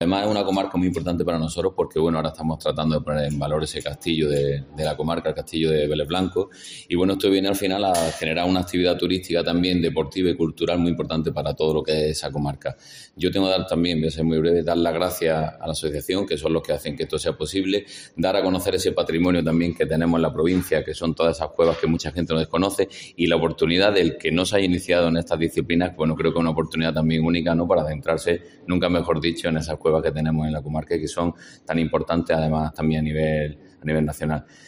Delegado de Cultura en Almería, José Vélez.